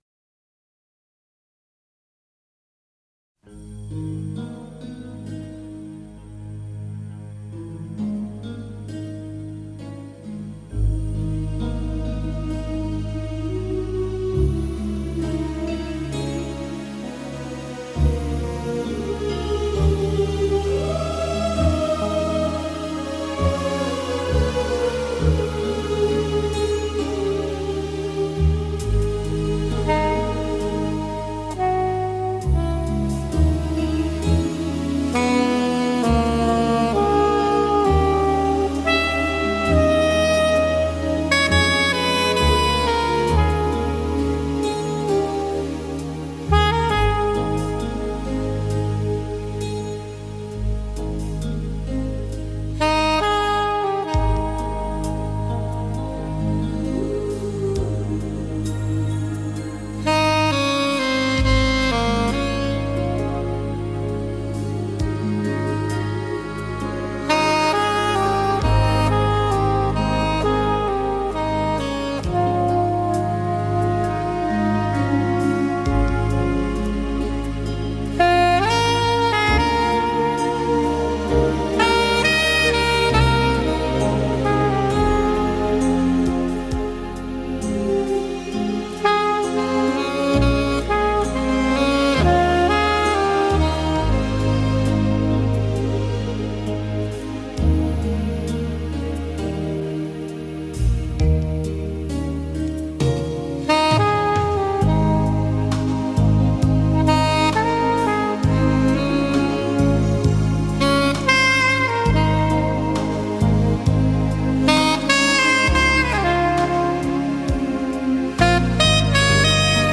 radiomarelamaddalena / STRUMENTALE / SAX /